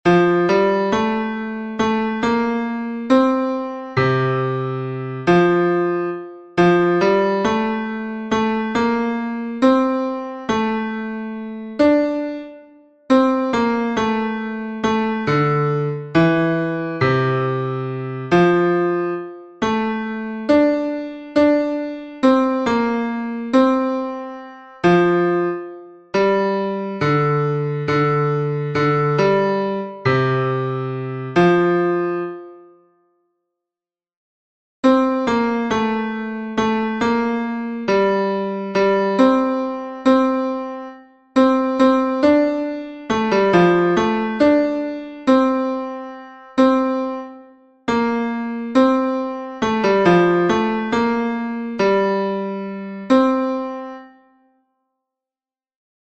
Fichier son Ténor